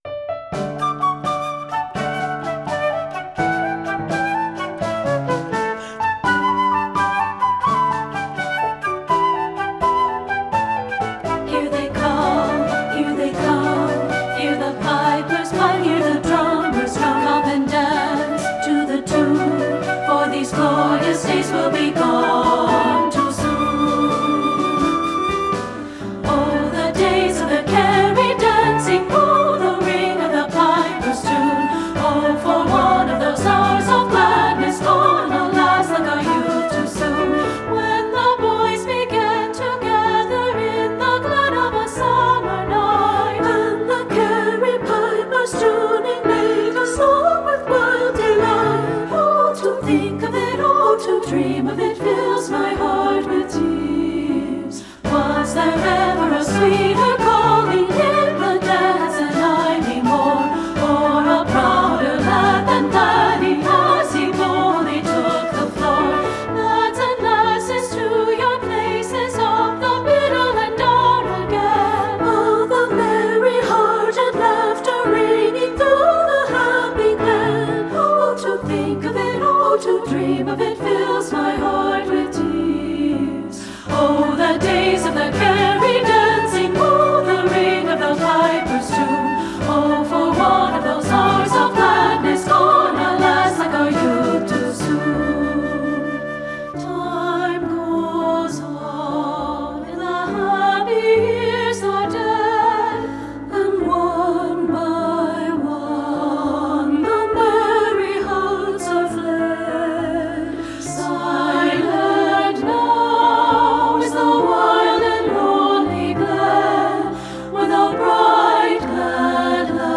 • Voice 1 (Part )
• Voice 2 (Part)
• Piano
• Flute (opt.)
• Drum (opt.)
Studio Recording
Ensemble: Treble Chorus
Key: D major
Tempo: Brightly (q. = 84)
Text Source: Irish Folk song
Accompanied: Accompanied Chorus